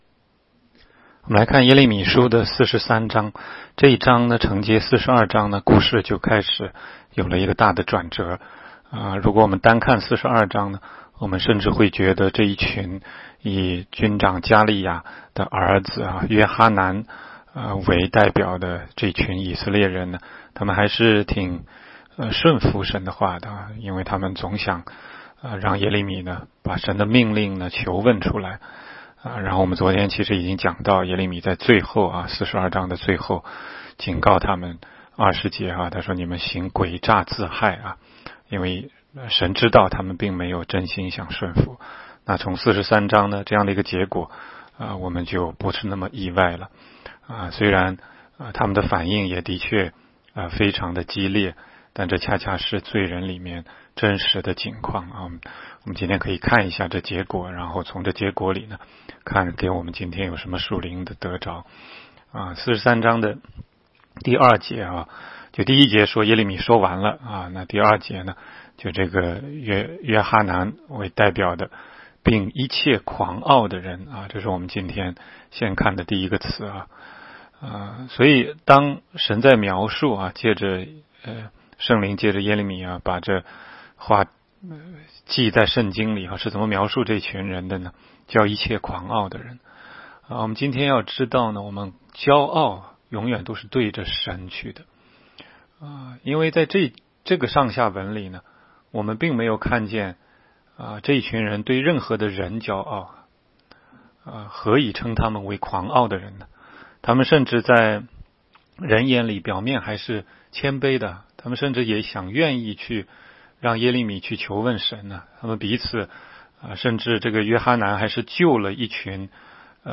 16街讲道录音 - 每日读经 -《耶利米书》43章